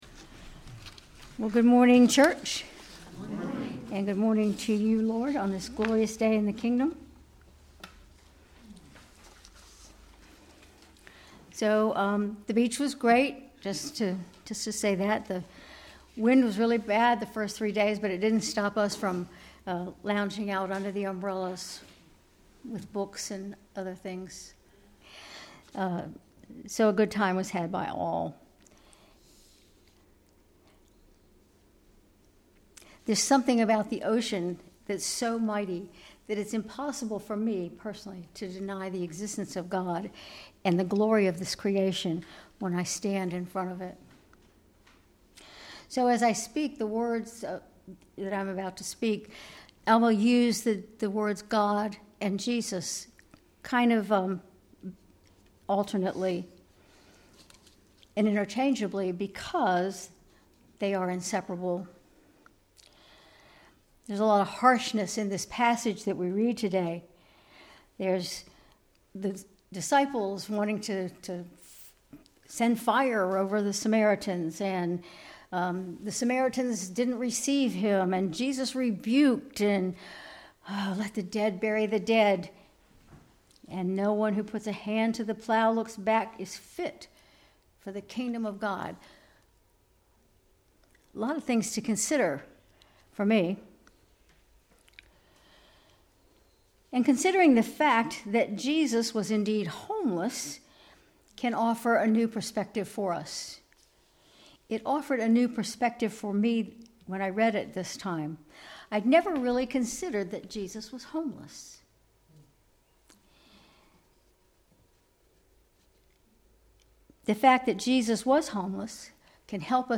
Sermon June 29, 2025